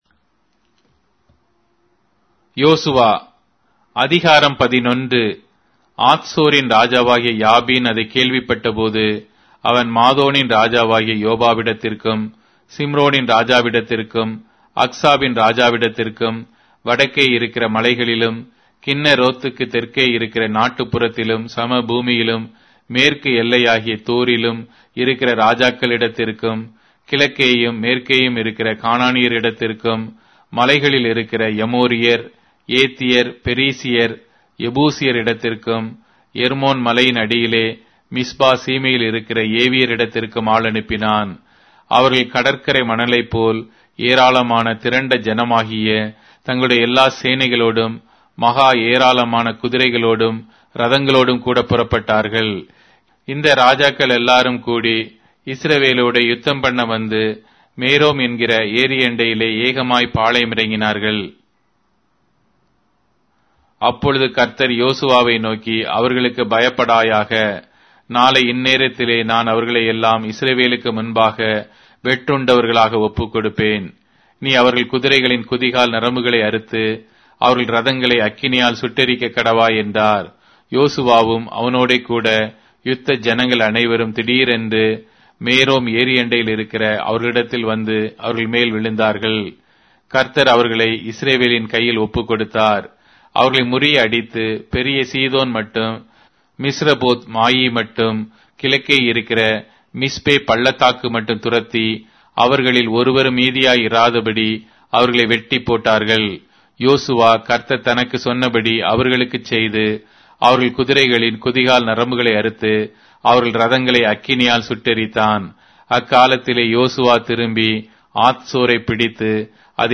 Tamil Audio Bible - Joshua 10 in Irvgu bible version